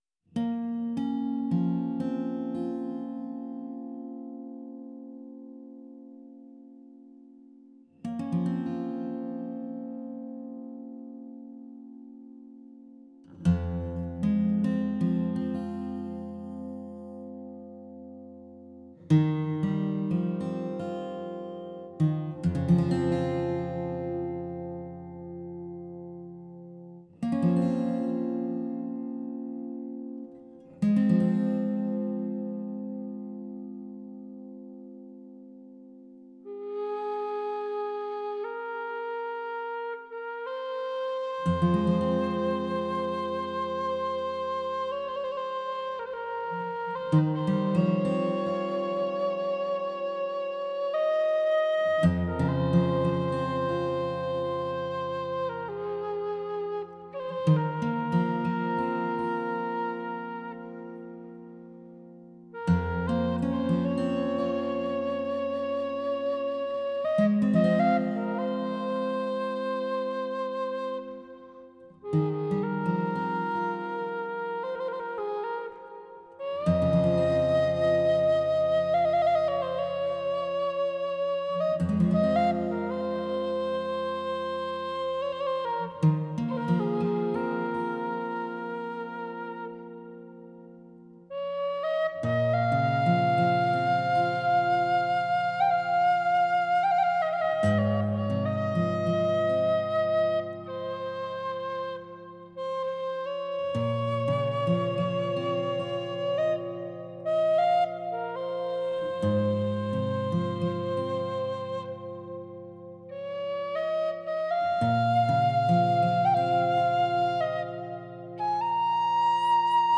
موسیقی ملل فولک